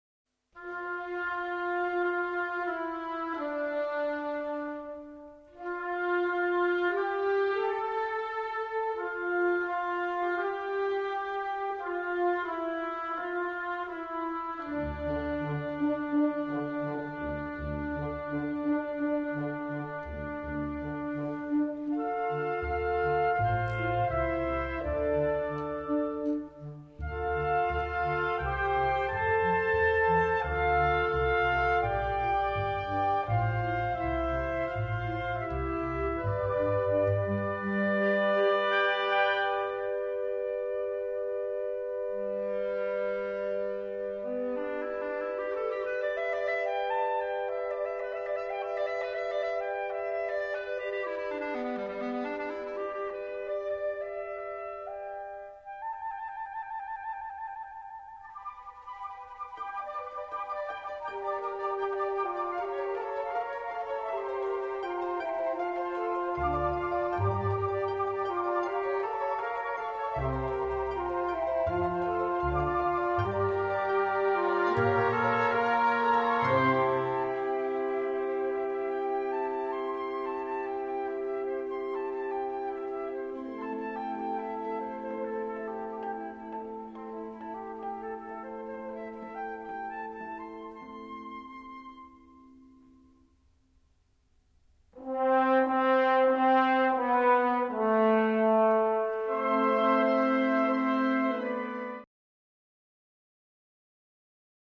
D minor（原調）
比較的原曲に忠実な編曲ですが、吹奏楽での演奏効果を考え鍵盤打楽器を駆使して色彩的に仕上げました。